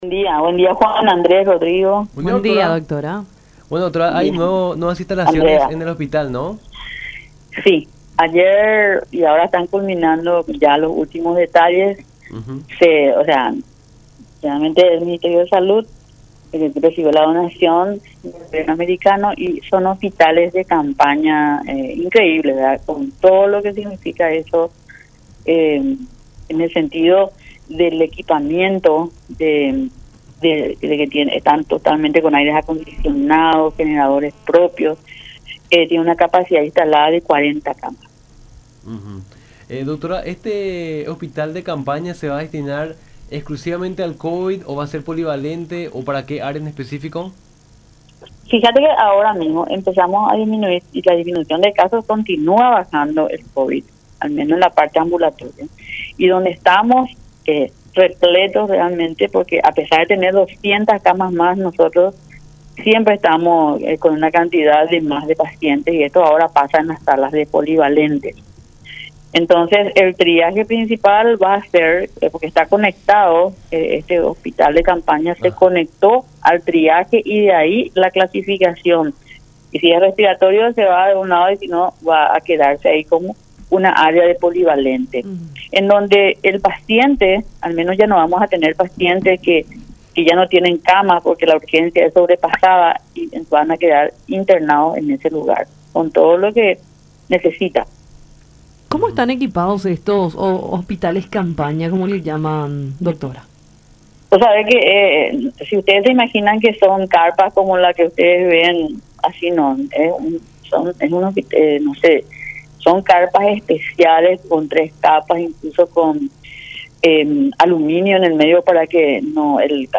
en conversación con Nuestra Mañana por La Unión.